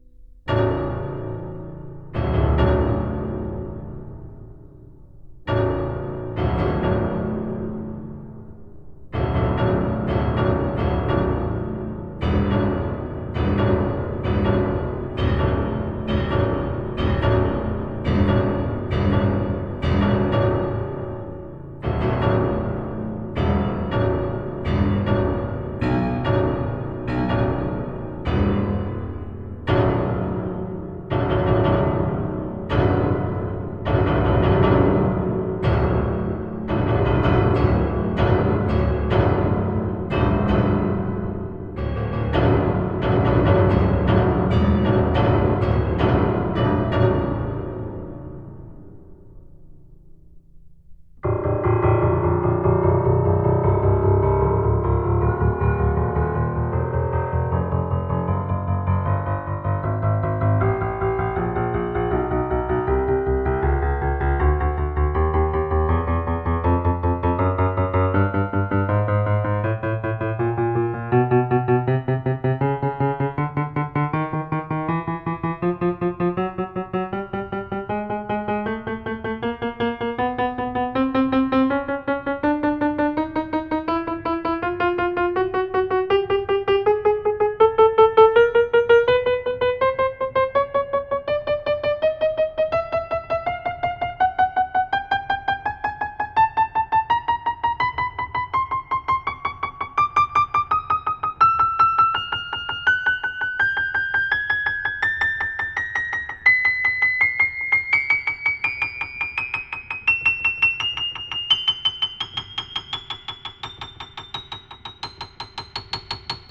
05 Piano low_high.wav